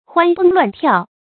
歡蹦亂跳 注音： ㄏㄨㄢ ㄅㄥˋ ㄌㄨㄢˋ ㄊㄧㄠˋ 讀音讀法： 意思解釋： 形容青少年健康活潑、生命力旺盛的樣子。